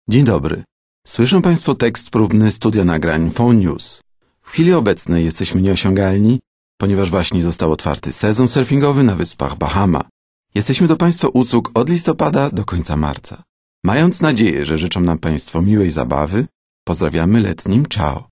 Sprecher polnisch, Übersetzer und Autor von Theaterstücken, Kurzerzählungen und Novellen.
Sprechprobe: Industrie (Muttersprache):